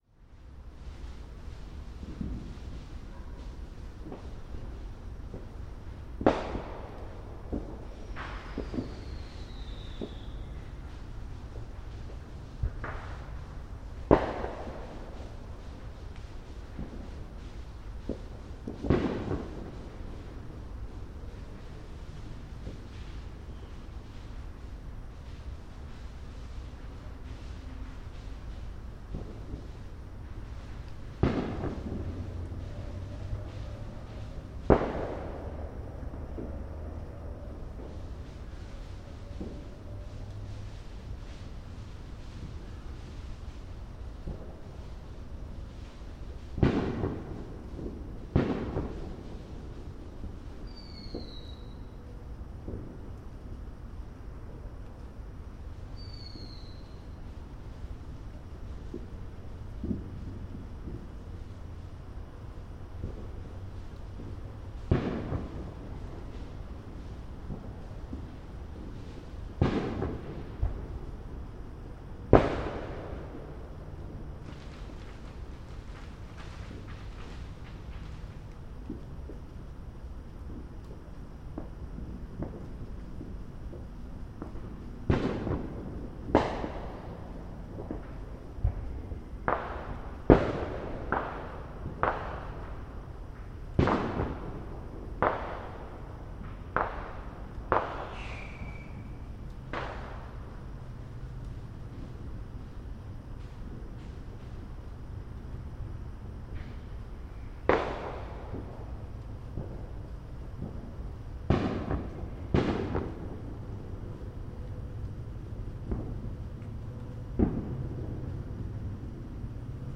Ten minutes of Fourth of July fireworks from my neighborhood
Here's a recording from my back yard on July 04, 2013, as the neighbors took advantage of the fact that fireworks are now legal to buy in North Carolina. This was recorded with an Olympus LS14 and a set of Sound Professionals master series BMC3 microphones in croakie mounts. Due to the nature of this recording, the use of headphones is recommended.